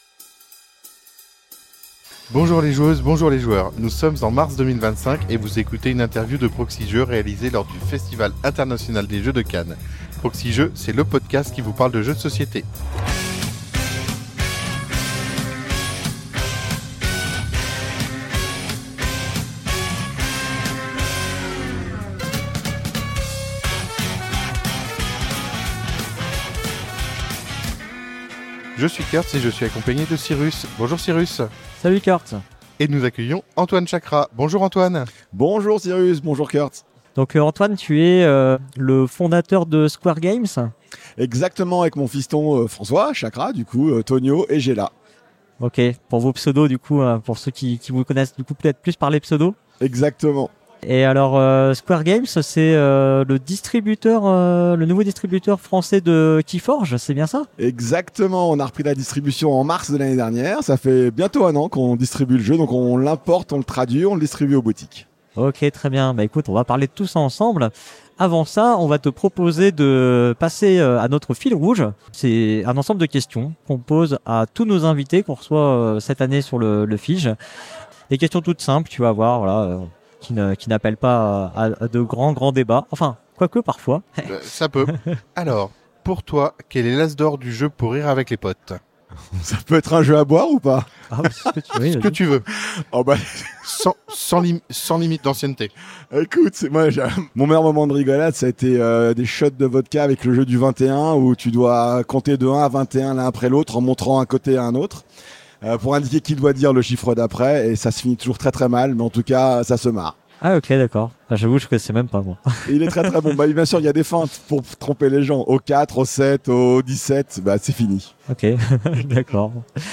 Lors de son séjour au Festival International des Jeux de Cannes, la Proxi-Team a rencontré de nombreuses actrices et de nombreux acteurs du monde du jeu de société.